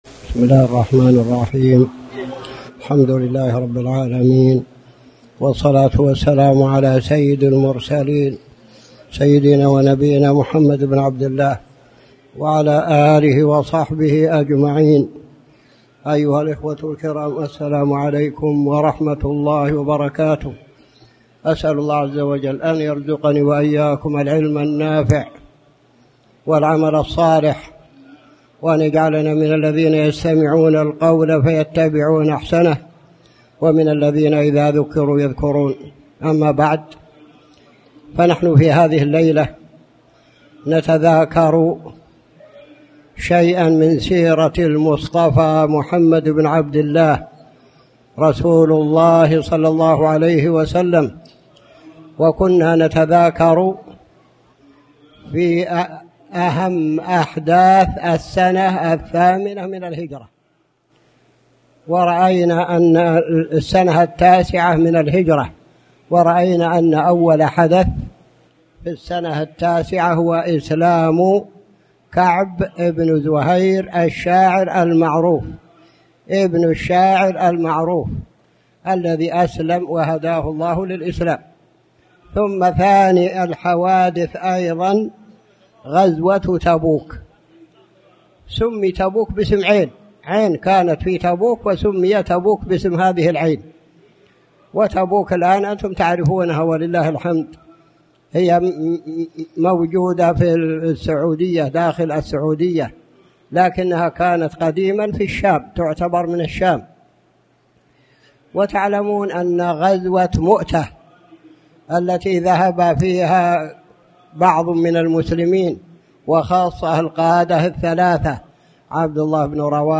تاريخ النشر ٢٨ محرم ١٤٣٩ هـ المكان: المسجد الحرام الشيخ